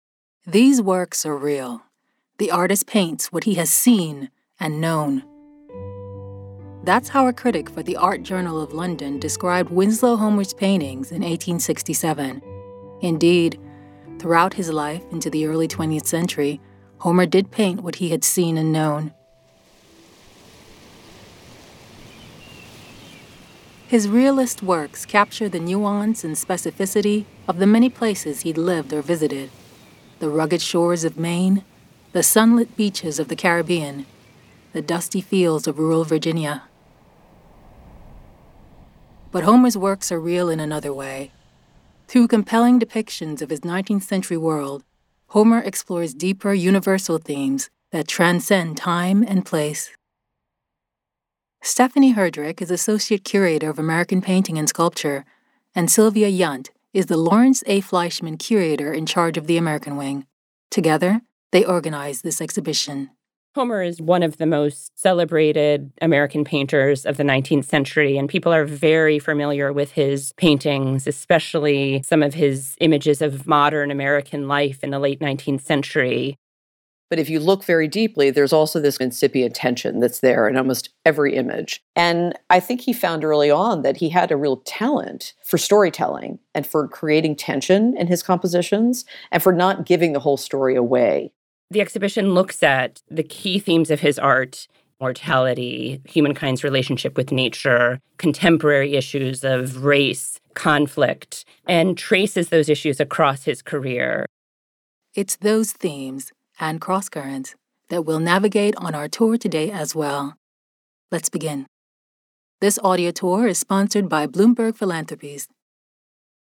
00_introduction_alt-music-fades-before-sfx.mp3